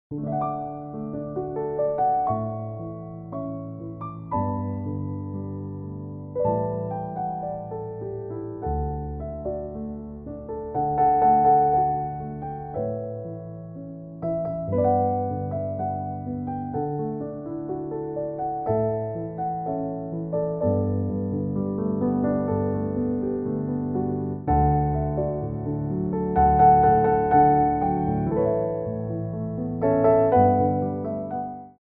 33 Tracks for Ballet Class.
Pliés 2
4/4 (8x8)